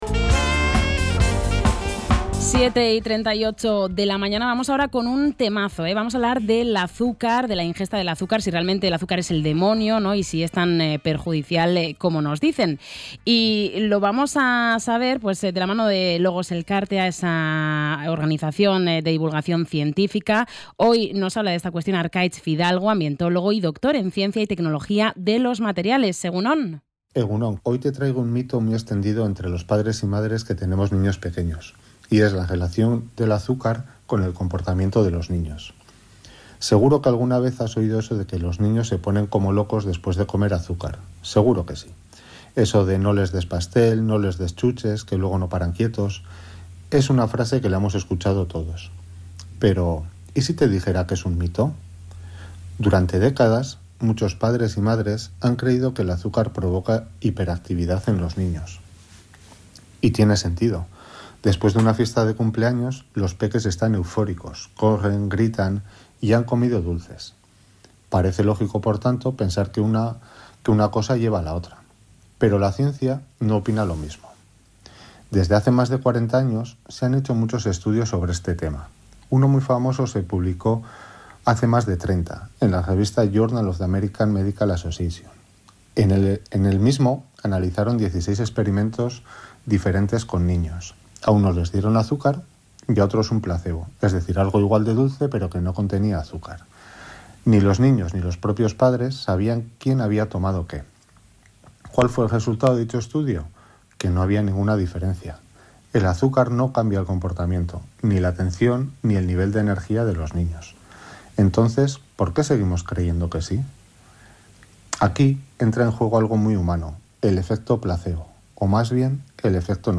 El invitado ha matizado que que el azúcar no cause hiperactividad no significa que sea inocuo.